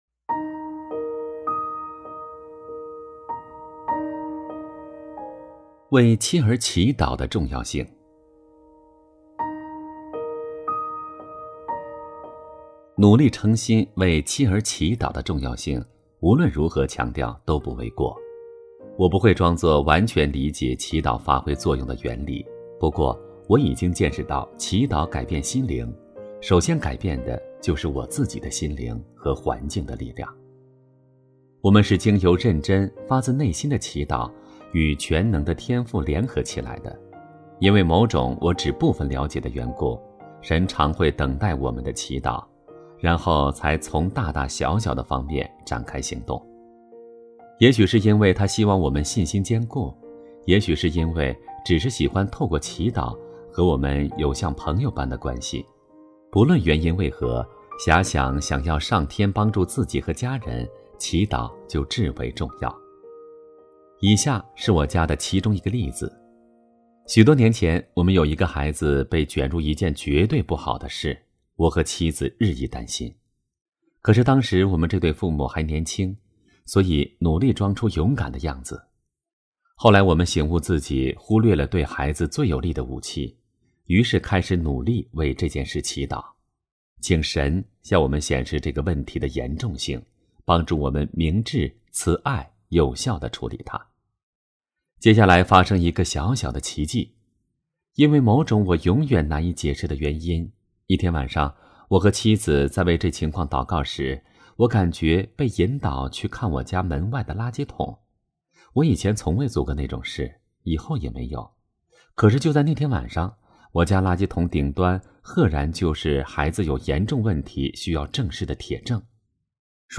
首页 > 有声书 > 婚姻家庭 | 成就好爸爸 | 有声书 > 成就好爸爸：28 为妻儿祈祷的重要性